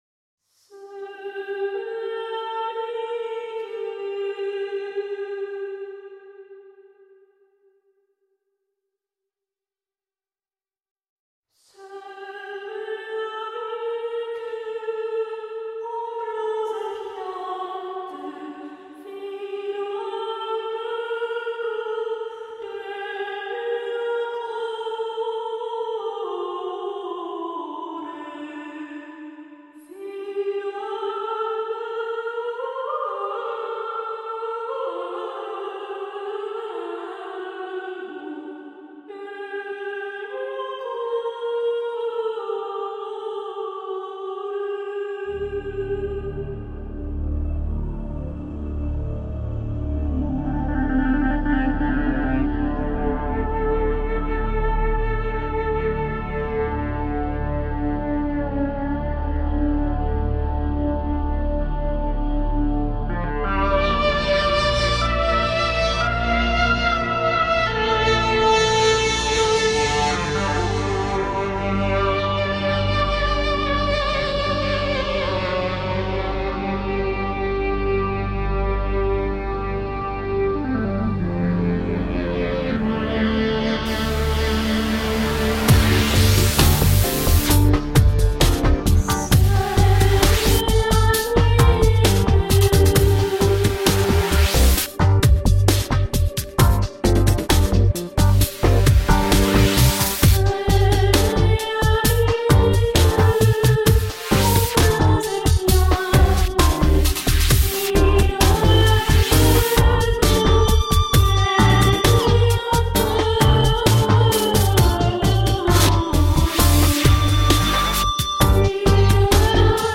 Vivid world-electronica grooves.